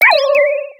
Cri de Funécire dans Pokémon X et Y.